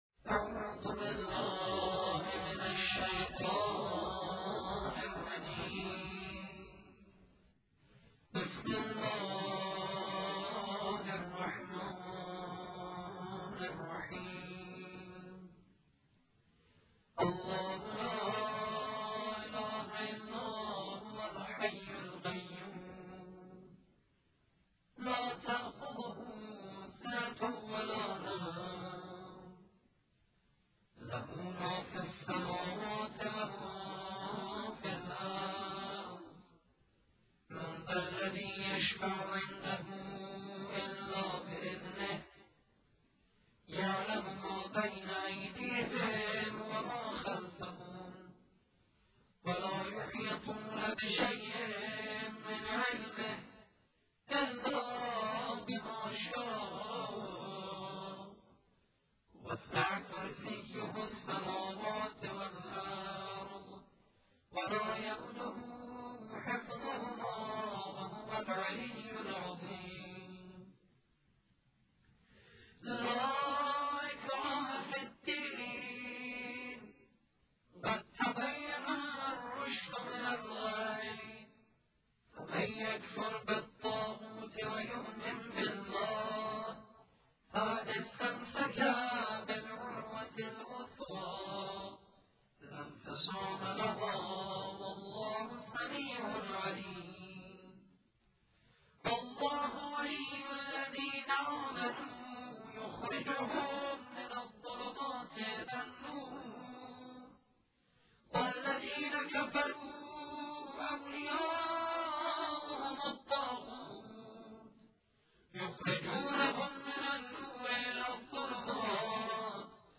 قرائت آیه الکرسی